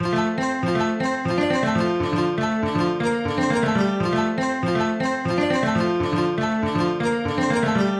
标签： 120 bpm Folk Loops Guitar Acoustic Loops 1.35 MB wav Key : F
声道立体声